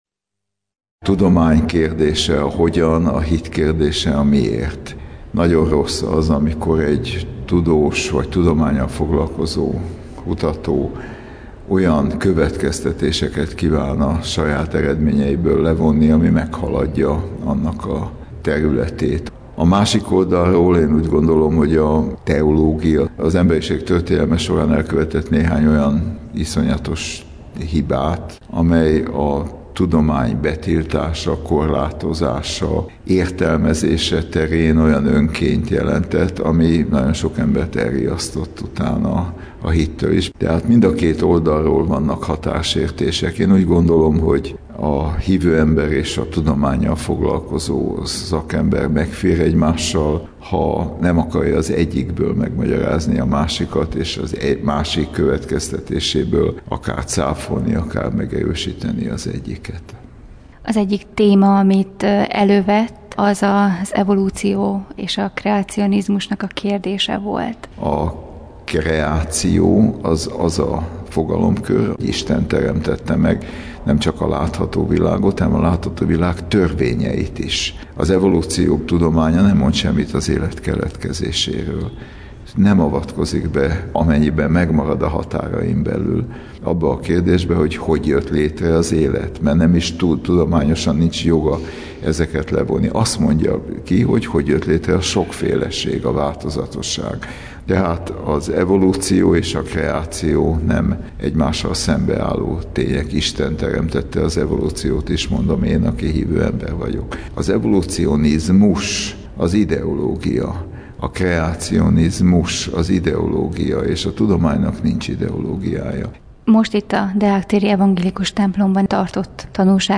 Tanúság: Falus András és Kubik Anna